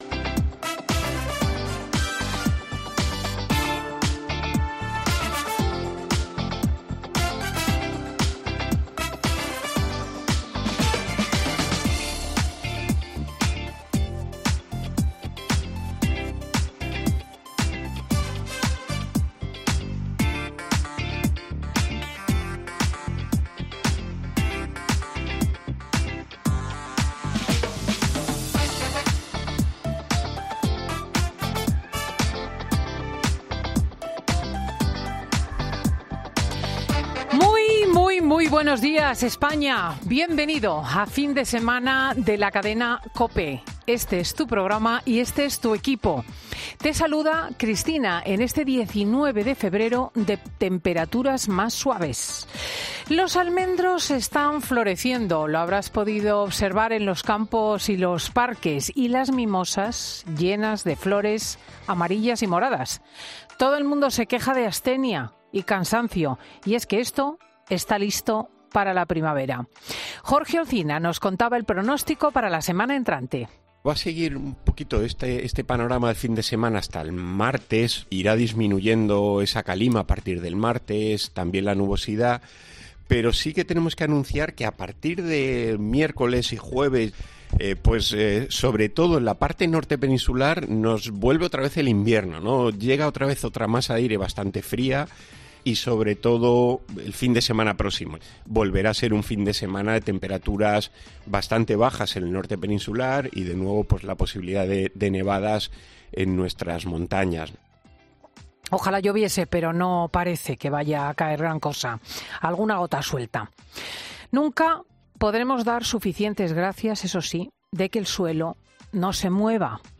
Ya puedes escuchar el monólogo de Cristina López Schlichting de este domingo 19 de febrero de 2023